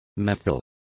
Complete with pronunciation of the translation of methyls.